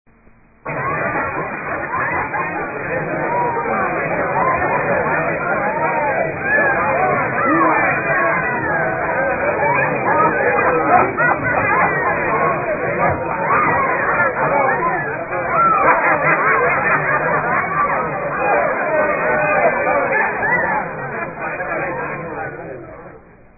Sound Effects (Instructions: play)
Party Scene